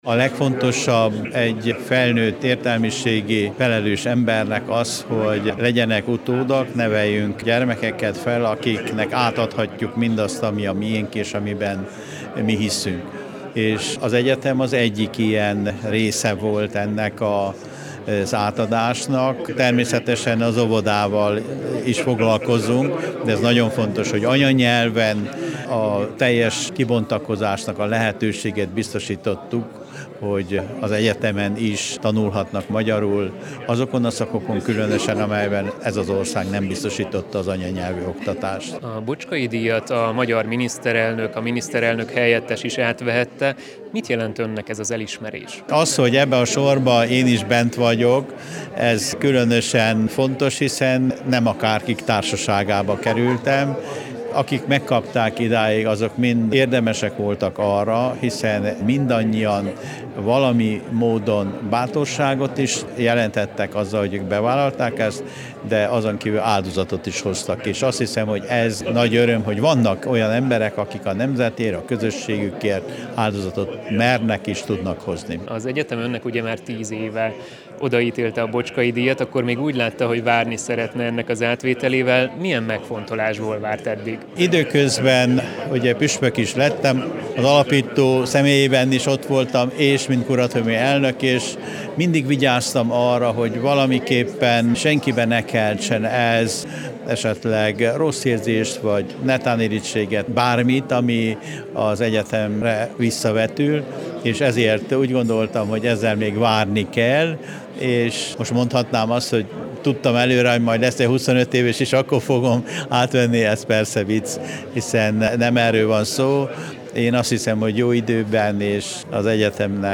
beszélgetett a díjátadó után